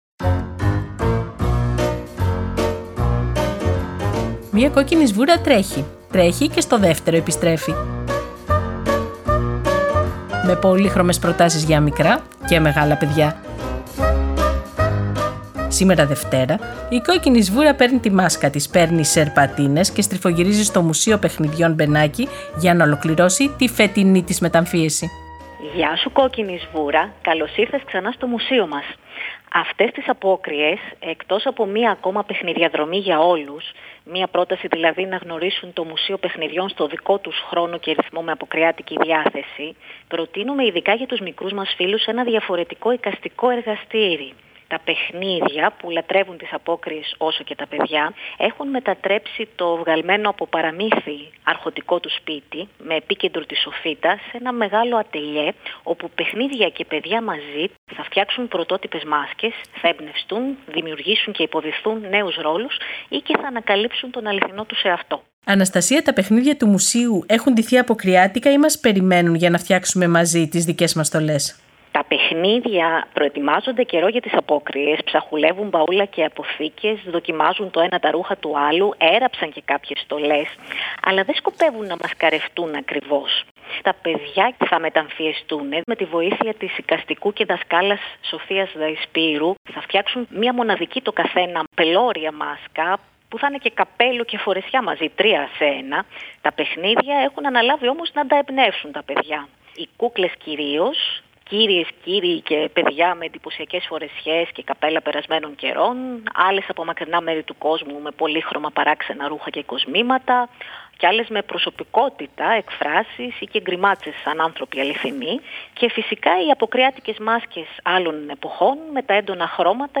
Σήμερα Δευτέρα η Κόκκινη Σβούρα στριφογυρίζει σε ένα Αποκριάτικο Εργαστήριο στο Μουσείο Μπενάκη- Παιχνιδιών  και παρέα με κούκλες και κούκλους σχεδιάζουν και φτιάχνουν μοναδικές μάσκες.